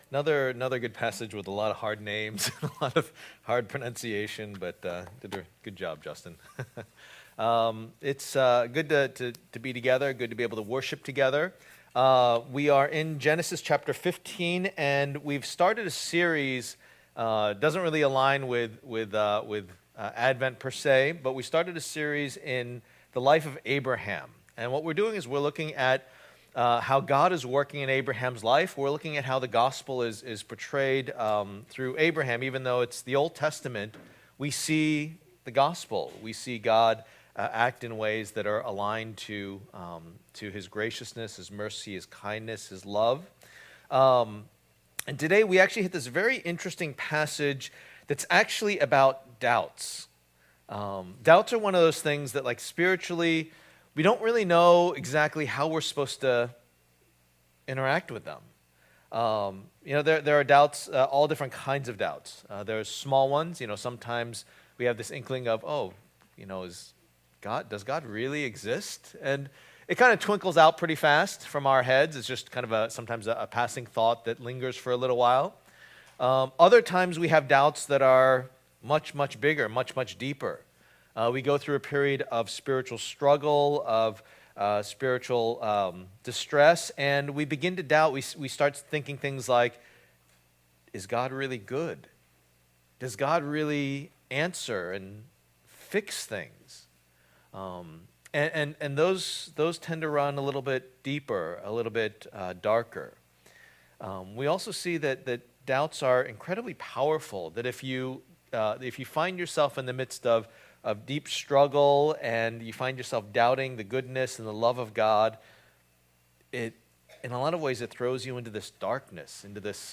Passage: Genesis 15:1-21 Service Type: Lord's Day